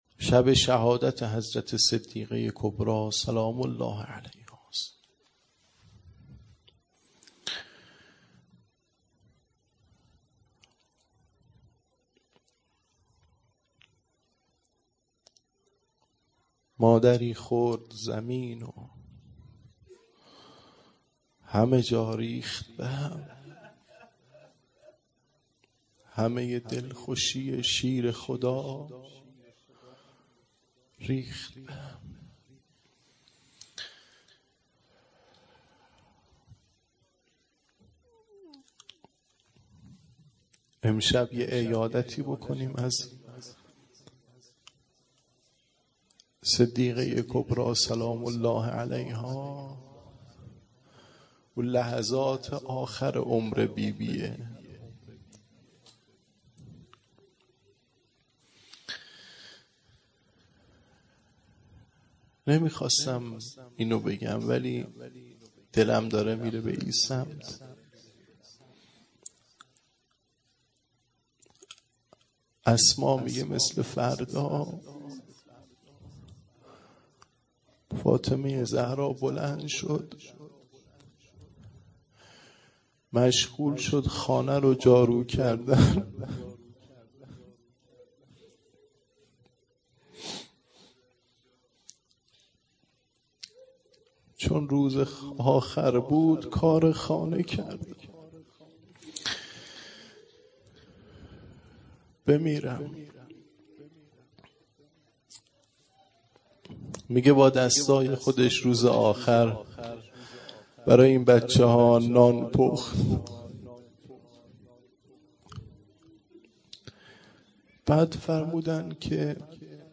دهه اول فاطمیه ۱۴٠۱